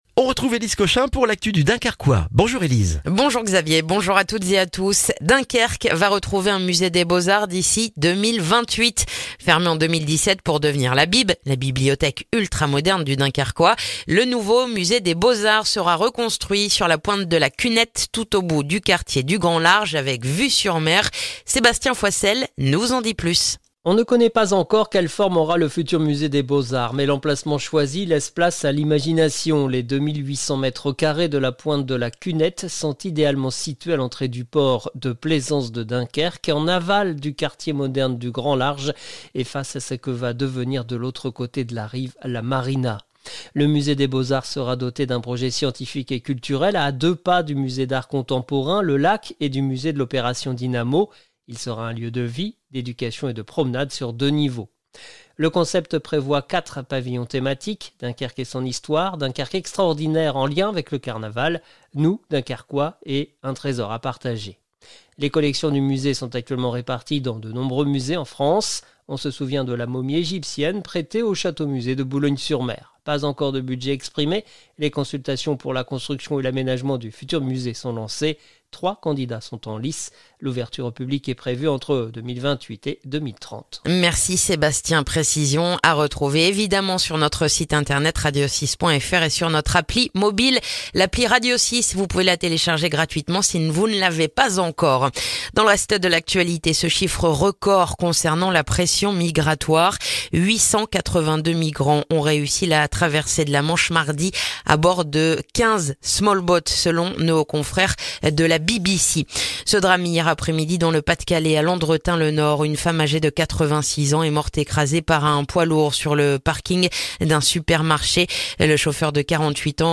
Le journal du jeudi 20 juin dans le dunkerquois